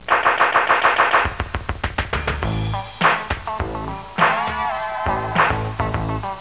“FUNKY DEMO” mode.